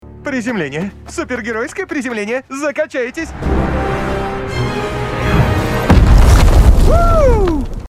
Супергеройские звуки для монтажа видео.
1. Супергеройское приземление из фильма «Дэдпул»
supergeroiskoe-prizemlenie-dedpul.mp3